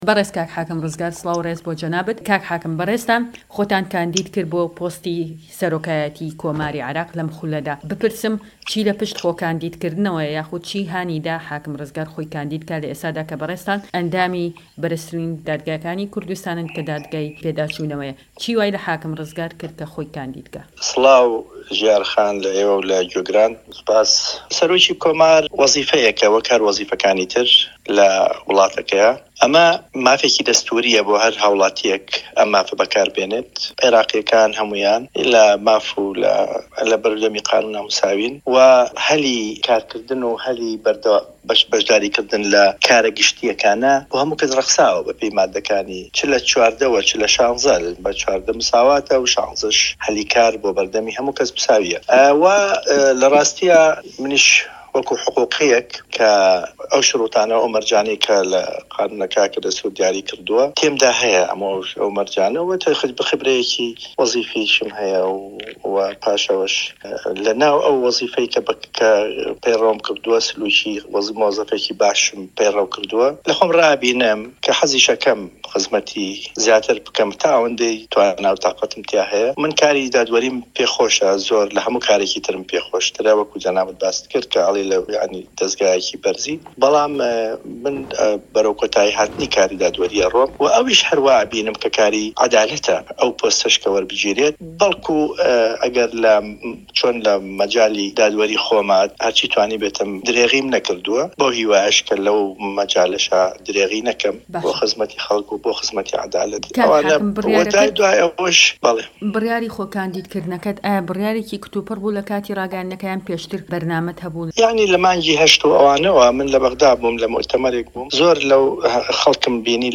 وتووێژ لەگەڵ دادوەر ڕزگار محەمەد ئەمین